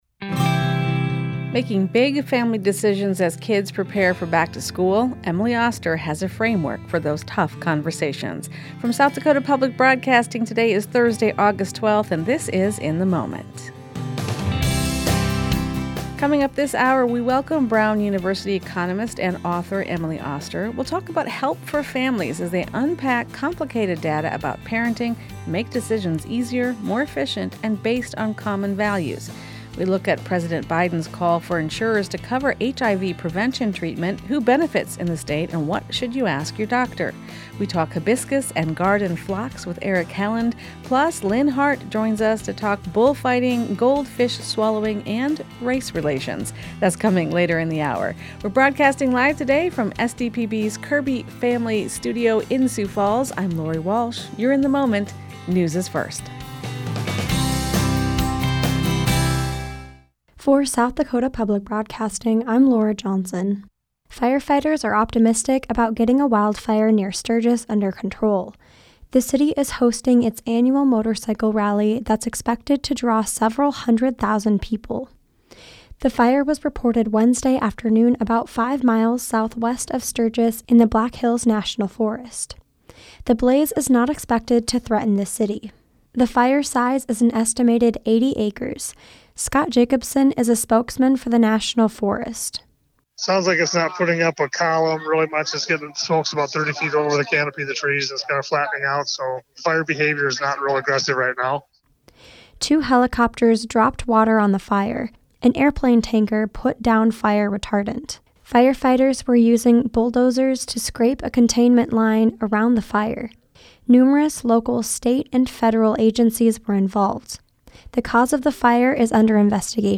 This story comes from a recent interview on SDPB's weekday radio program, " In the Moment ."